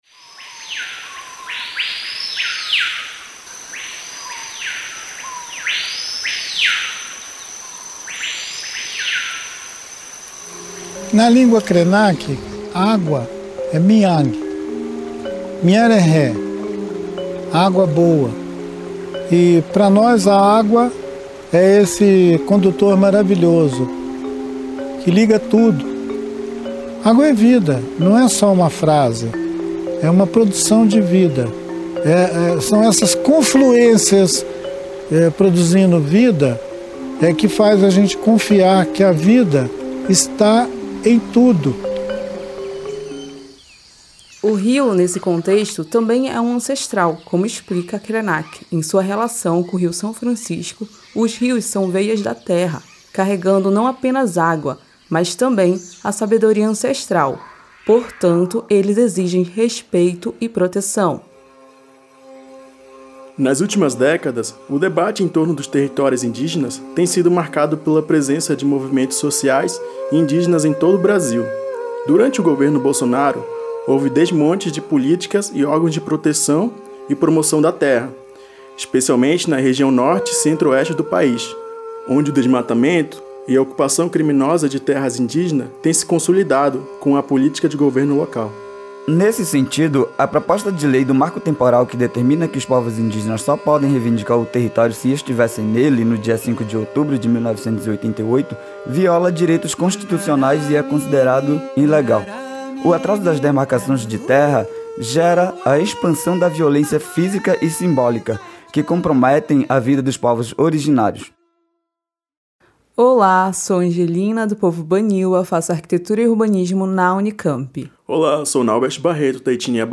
Neste episódio, convidados de três etnias falam sobre a importância dos territórios para indígenas do Brasil e andinos do Peru.